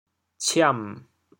潮阳拼音“ciam3”的详细信息
ciam3.mp3